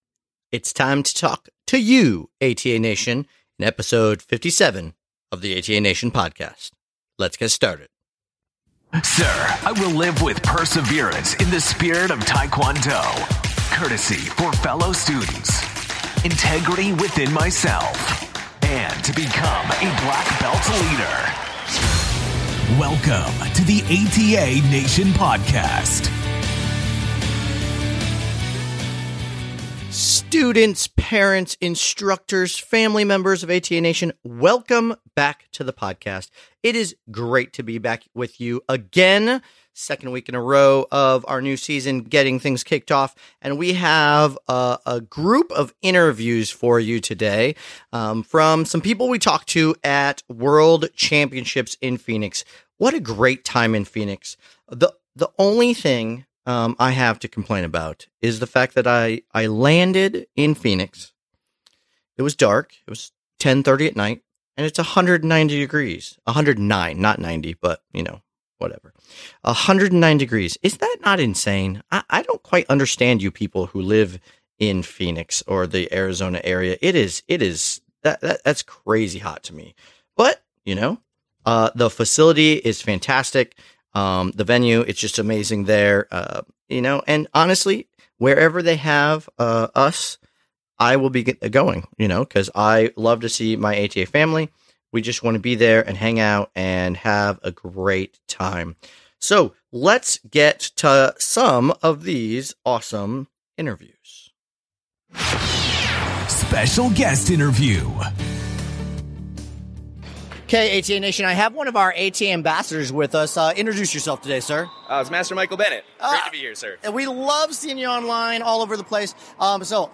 Ep. 57 - Interviews for ATA World Championships
We ran into a lot of awesome residents of ATA Nation at the 2022 ATA World Championships. In this episode we introduce you to a few of them and some interesting facts about them.